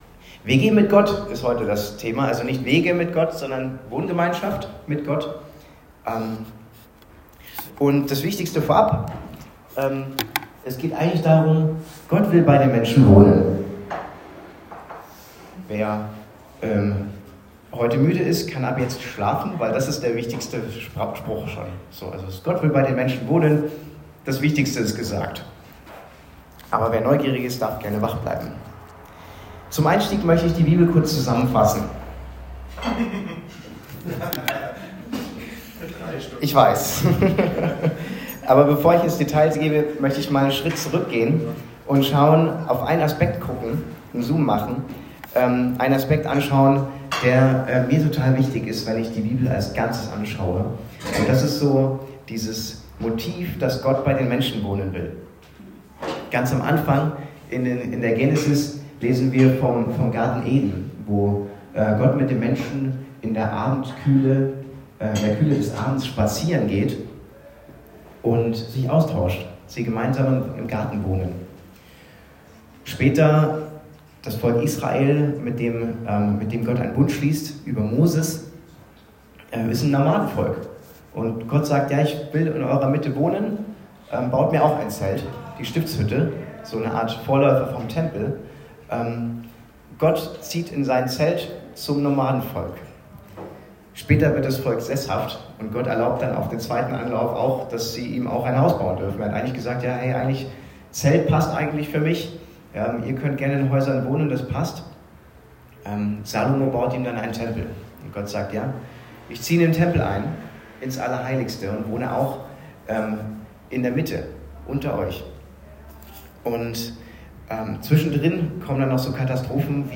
Was passiert, wenn Gottes Geist nicht draußen bleibt, sondern einzieht? Eine Predigt